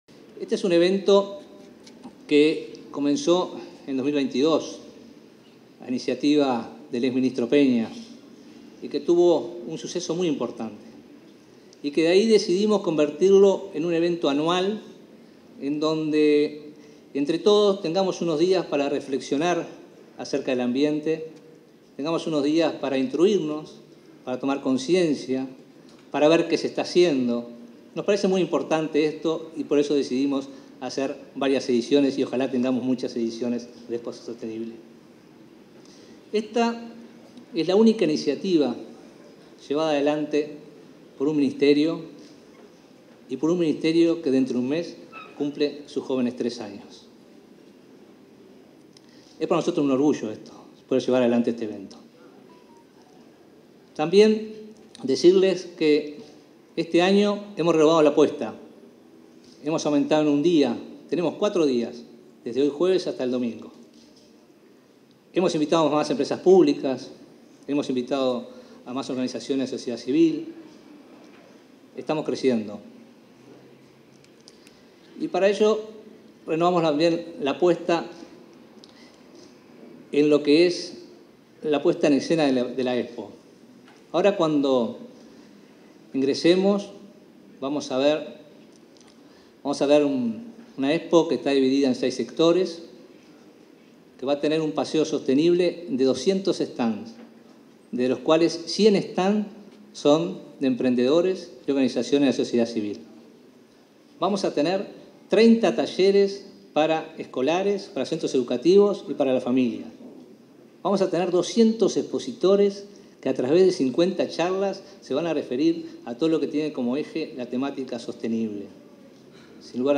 Palabras del ministro de Ambiente, Robert Bouvier
En el marco de la apertura de Expo Uruguay Sostenible, este 8 de junio, se expresó el ministro de Ambiente, Robert Bouvier.